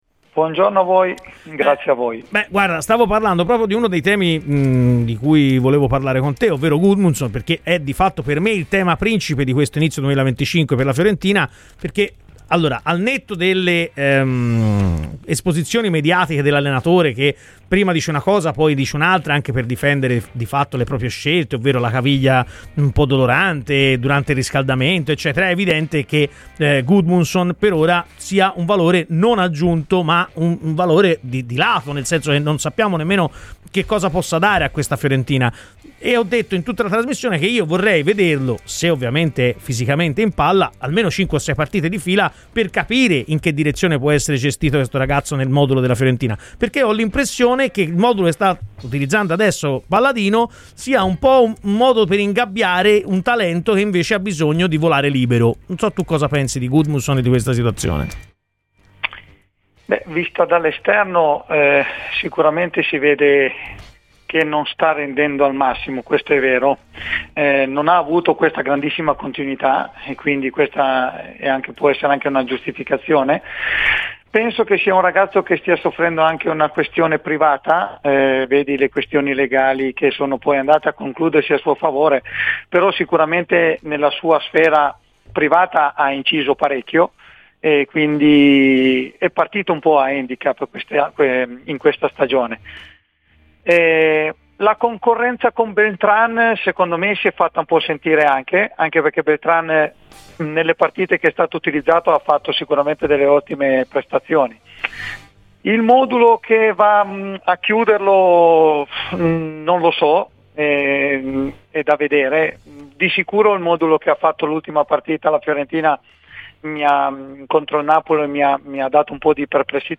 Nella mattinata di Radio FirenzeViola, durante la trasmissione "Chi si compra?" l'ex attaccante della Fiorentina Enrico Fantini - protagonista della promozione dei viola dalla Serie B alla A nel 2004 - ha detto la sua sulla prima parte di stagione della squadra di Palladino.